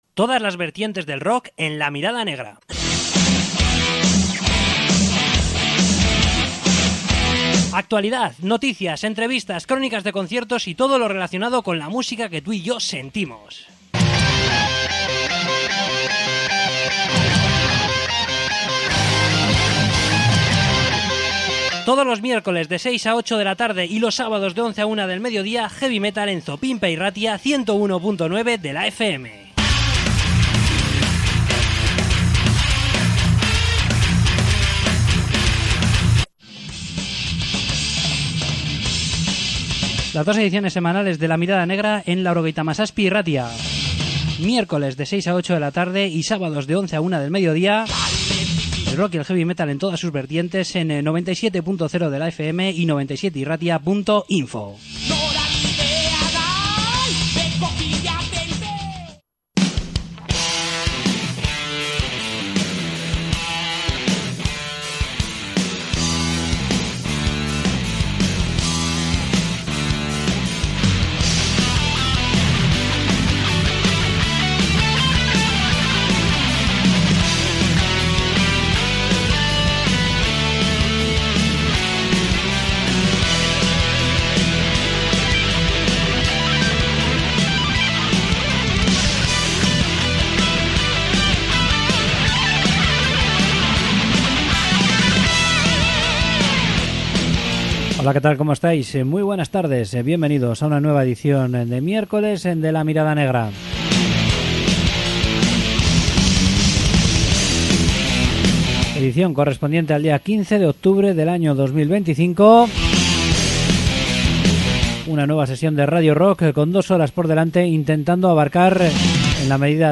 Entrevista con Embloodyment